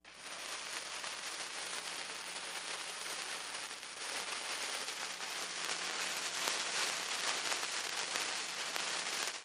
Fireworks, Fuse Burning.